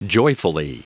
Prononciation du mot joyfully en anglais (fichier audio)
Prononciation du mot : joyfully